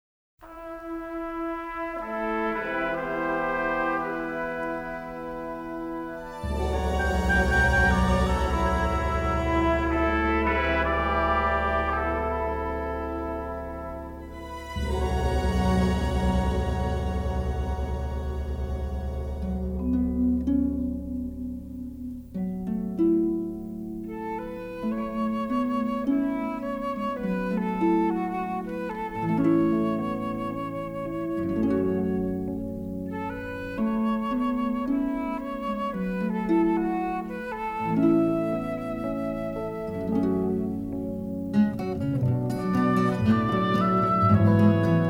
remixed and mastered from the original 1/2" stereo tapes.